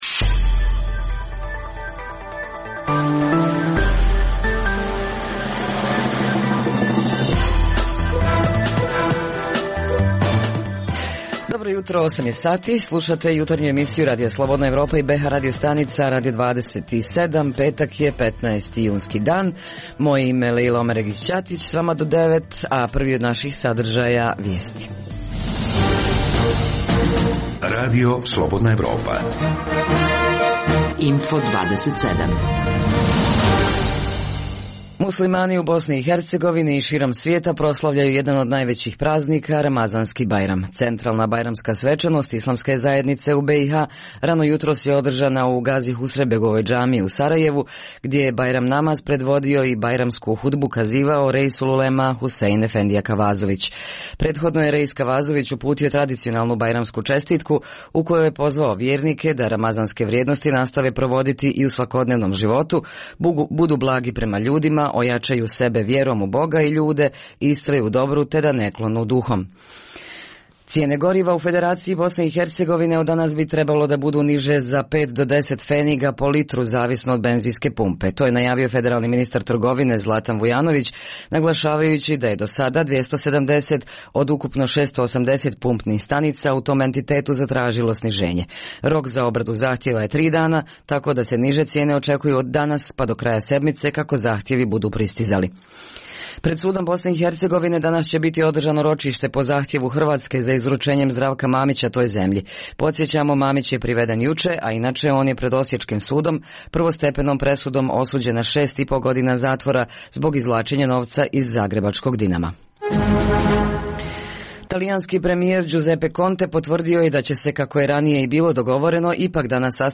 Danas je prvi dan Ramazanskog bajrama, a jutrošnja priča stiže iz Gračanice. Petkom u Behalendu osvrt na sedmične događaje u bh.gradovima, a za ovo jutro pregled sedmice donose naši dopisnici iz Tuzle, Prijedora, Goražda i Konjica.
Redovni sadržaji jutarnjeg programa za BiH su i vijesti i muzika.